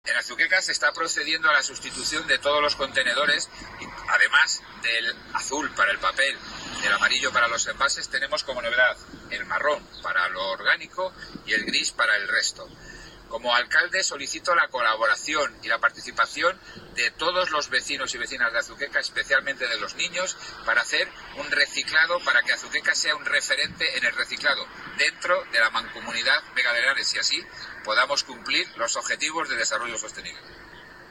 Declaraciones del alcalde José Luis Blanco